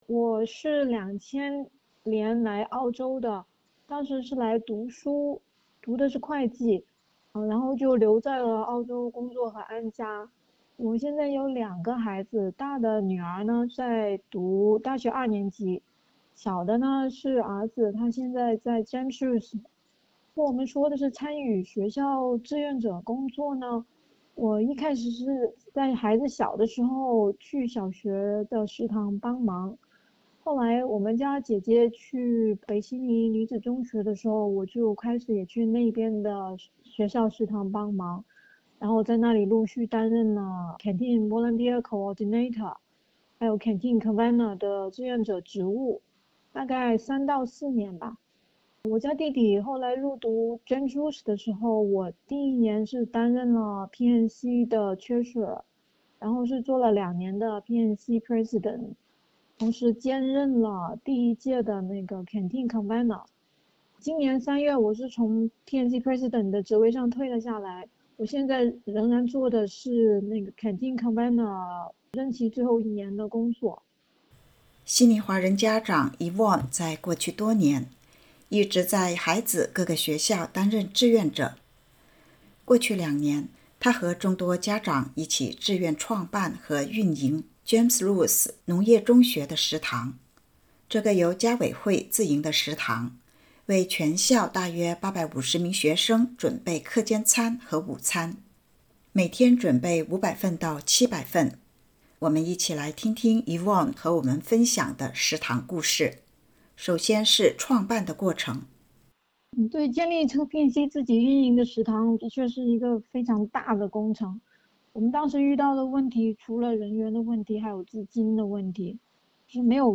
让孩子们吃好玩好学好是该校众多家庭的期望，他们的学校食堂故事“滋味”多多，一起来看看。（请点击音频收听采访报道）